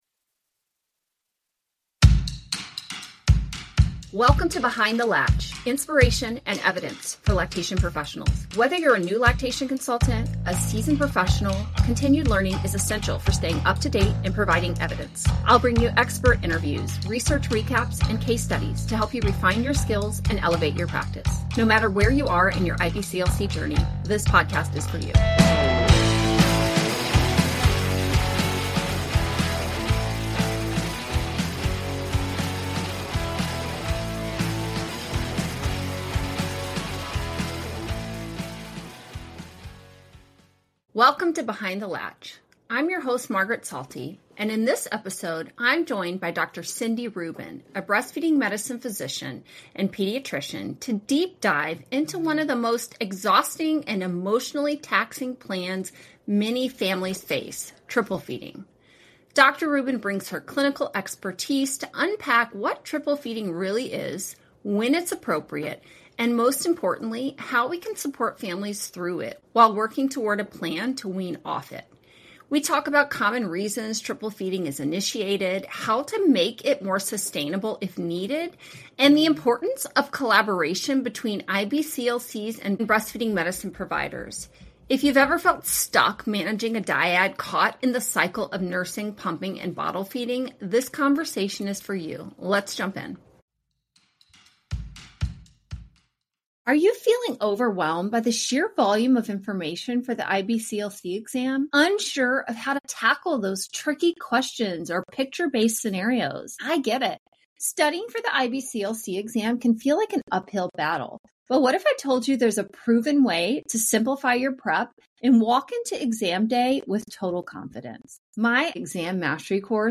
Triple Feeding, Scope of Practice, and Collaborative Care: A Conversation